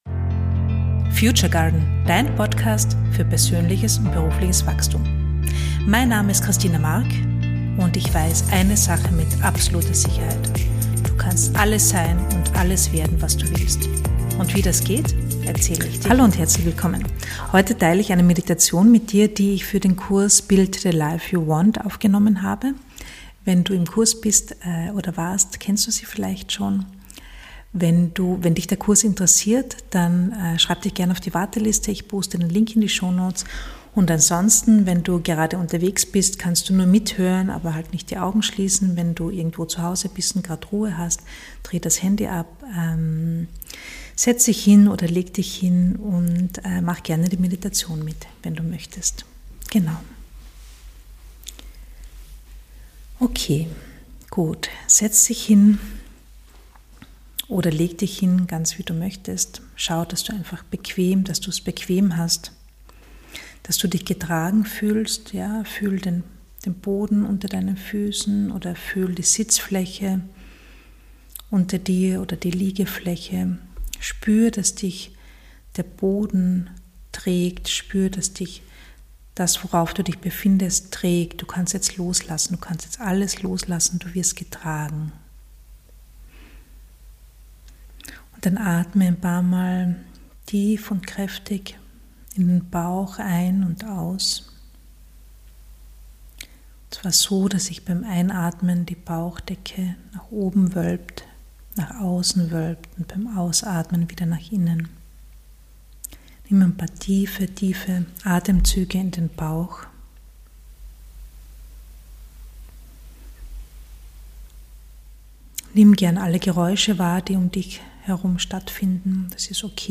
Ich habe dir eine kurze Meditation aufgenommen, die dir hilft, gute Gefühle in dir zu verankern.